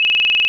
pingring.wav